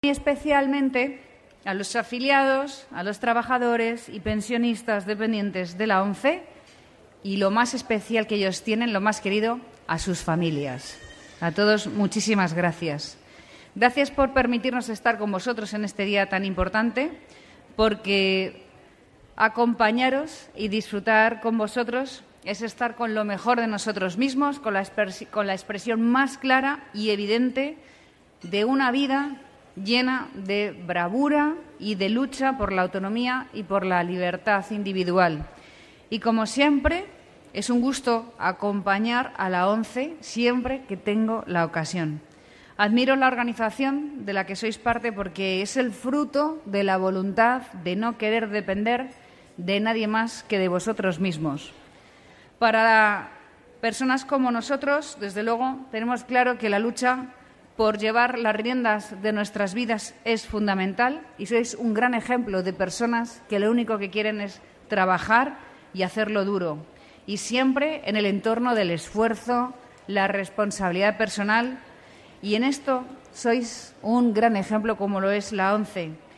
La presidenta de la Comunidad de Madrid acompañó a la ONCE en su fiesta de Santa Lucía